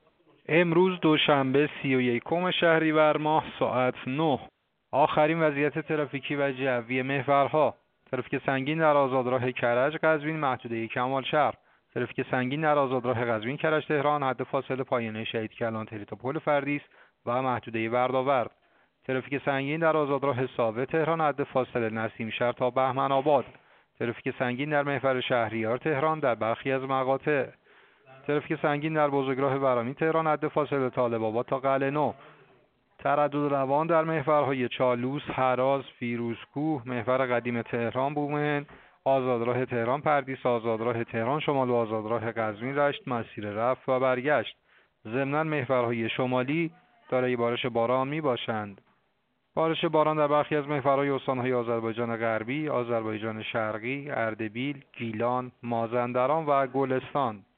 گزارش رادیو اینترنتی از آخرین وضعیت ترافیکی جاده‌ها ساعت ۹ سی‌ویکم شهریور؛